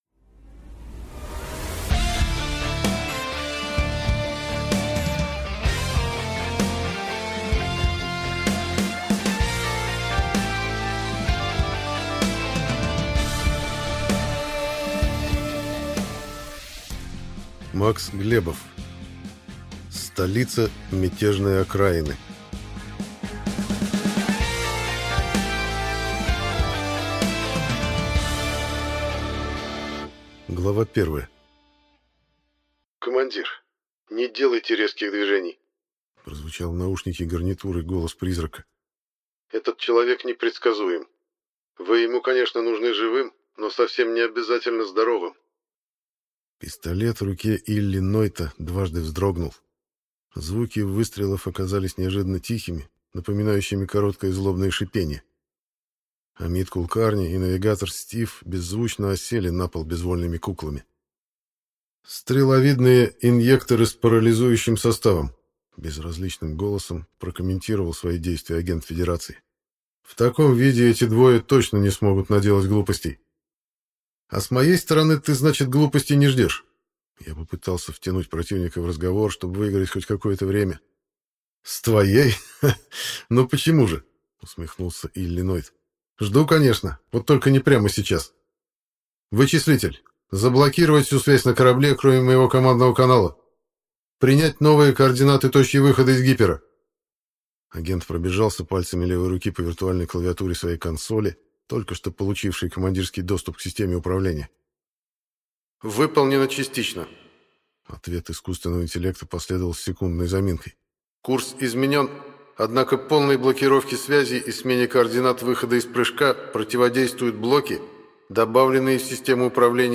Аудиокнига Столица мятежной окраины | Библиотека аудиокниг